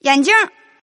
Index of /client/common_mahjong_tianjin/mahjongwuqing/update/1162/res/sfx/tianjin/woman/